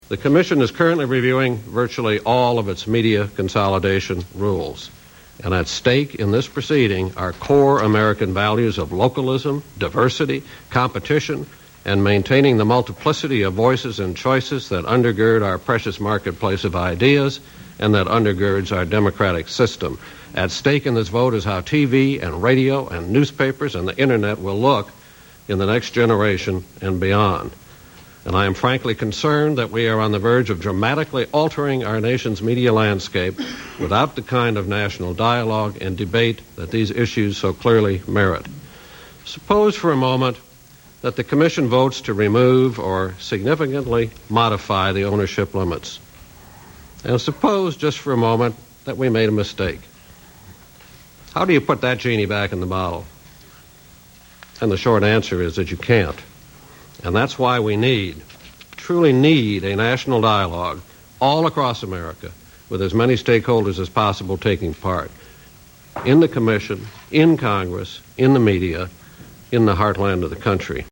Anyway, here's a few more choice utterances from the hearing - they are also in MP3 format:
Michael Copps opening statement: media concentration [1:10, 555K]